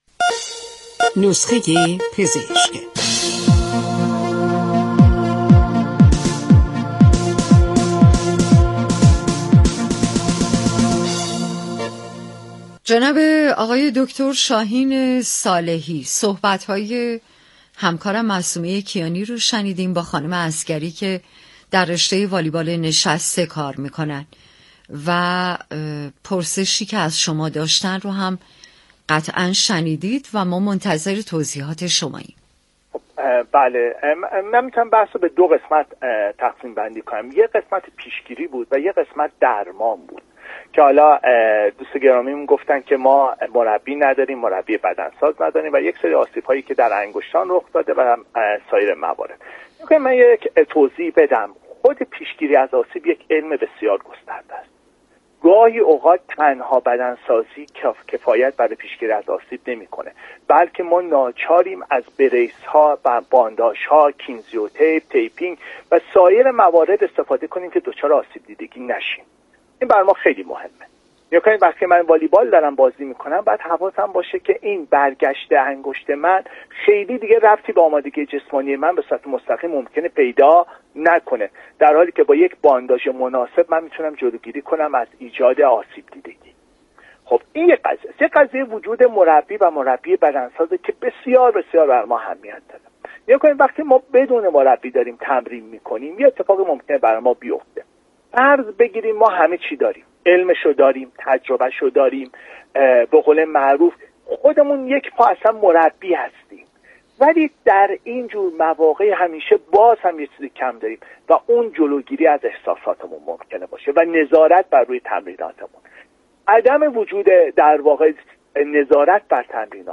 در گفت وگو با برنامه نسخه ورزشی رادیو ورزش